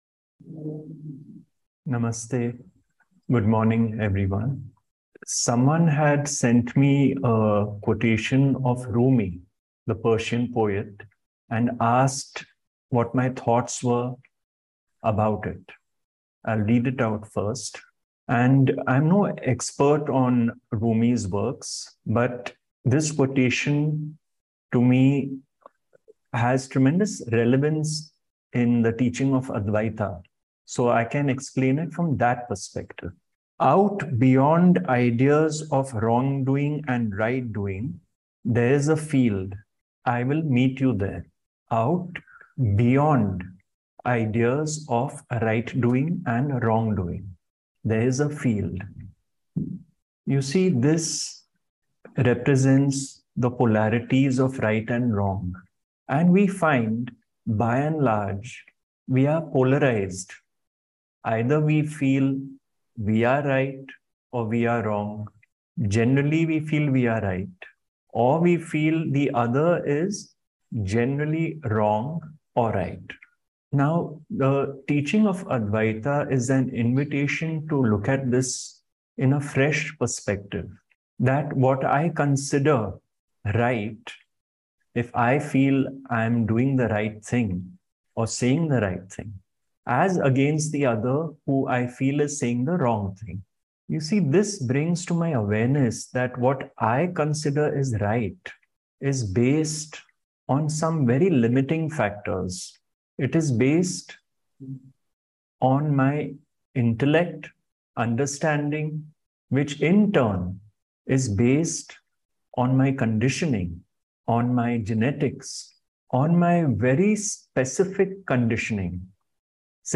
Out-Beyond-Ideas-of-Wrongdoing-and-Rightdoing-Zoom-Talk.mp3